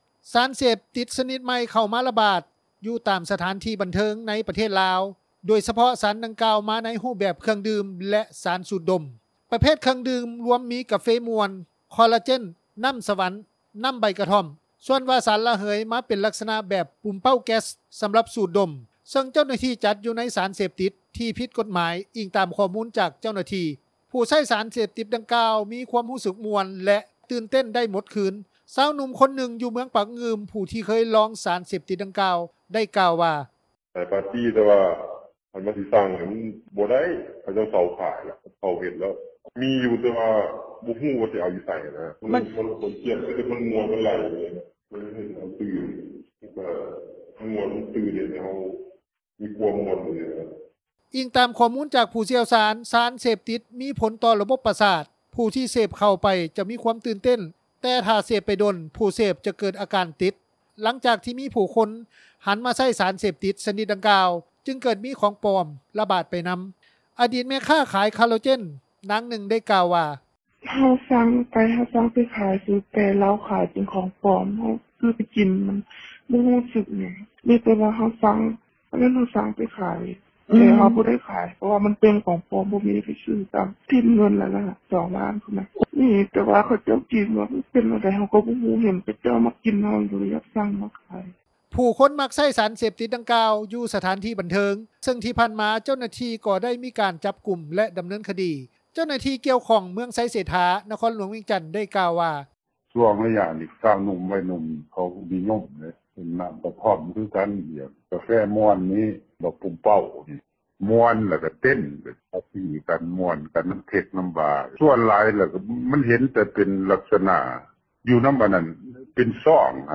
ສານເສບຕິດ ຊະນິດໃໝ່ ກໍາລັງຣະບາດ – ຂ່າວລາວ ວິທຍຸເອເຊັຽເສຣີ ພາສາລາວ
ຜູ້ທີ່ໃຊ້ສານເສບຕິດ ດັ່ງກ່າວ ຈະມີຄວາມຮູ້ສຶກມ່ວນ ແລະ ຕື່ນເຕັ້ນ ໄດ້ໝົດຄືນ. ຊາວໜຸ່ມຄົນນຶ່ງ ຢູ່ເມືອງປາກງື່ມ ຜູ້ທີ່ເຄີຍລອງ ສານເສບຕິດ ດັ່ງກ່າວ ໄດ້ກ່າວວ່າ: